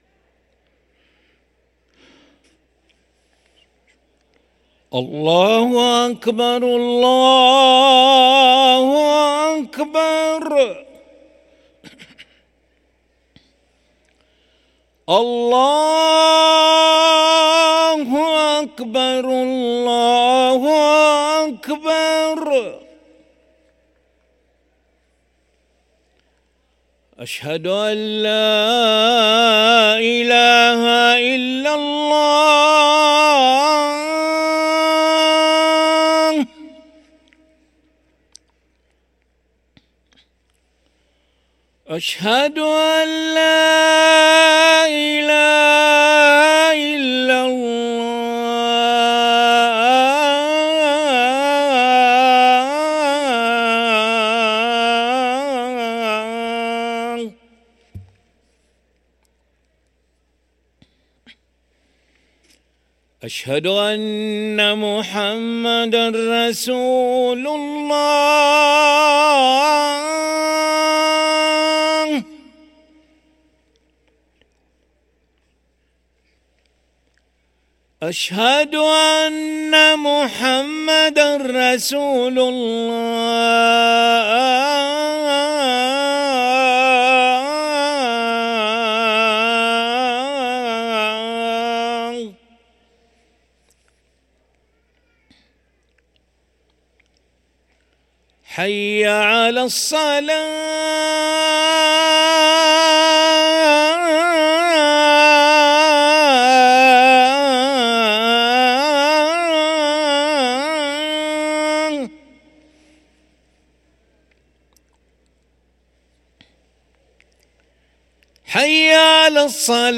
أذان العشاء للمؤذن علي ملا الأحد 11 صفر 1445هـ > ١٤٤٥ 🕋 > ركن الأذان 🕋 > المزيد - تلاوات الحرمين